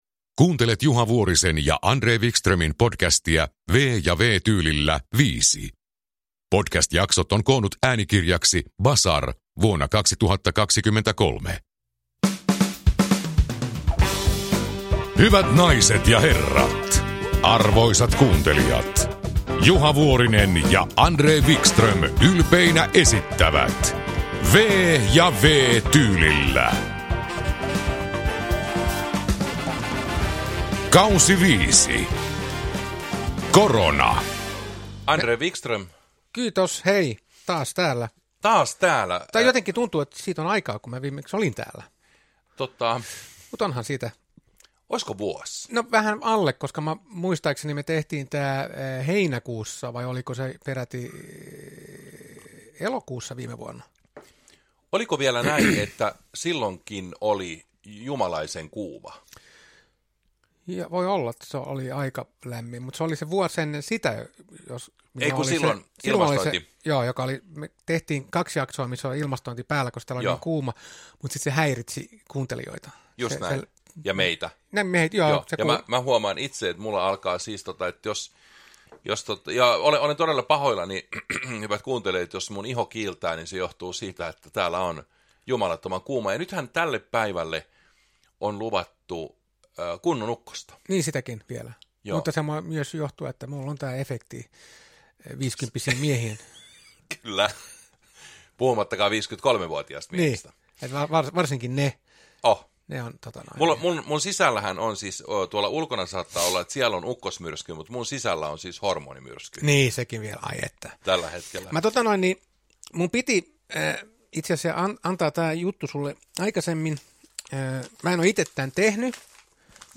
V- ja W-tyylillä K5 – Ljudbok
Humor Memoarer & biografier Njut av en bra bok
Uppläsare: Juha Vuorinen, André Wickström